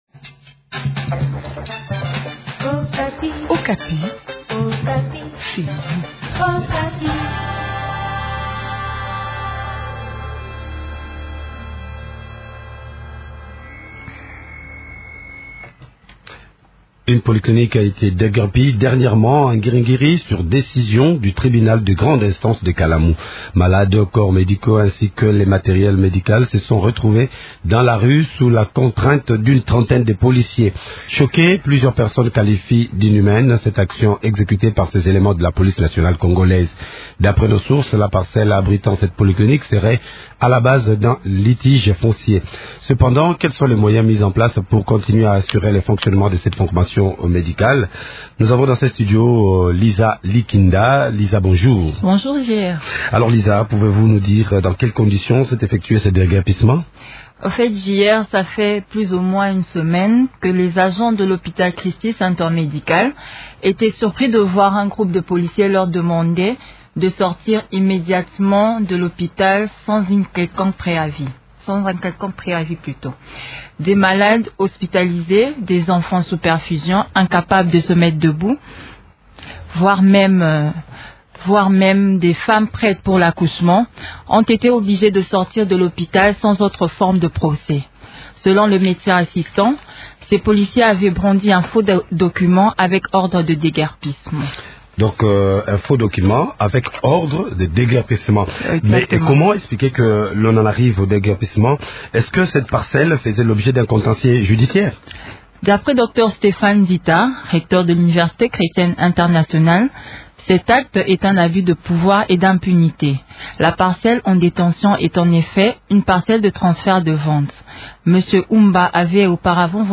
en parle avec Bonioma Iselonge, bourgmestre adjoint de la commune de Ngiri Ngiri.